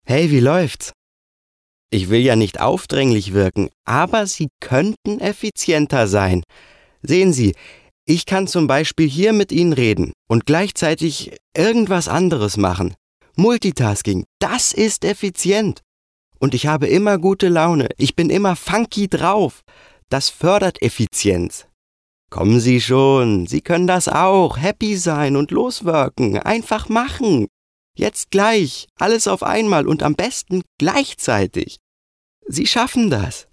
Sorry, Dein Browser unterstüzt kein HTML5 Stimmprobe - 1 Stimmprobe - 2 Stimmprobe - 3 Stimmprobe - 4 Stimmprobe - 5 Stimmprobe - 6 Stimmprobe - 7